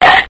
9. Cartoon Chomp
9-cartoon-chomp.mp3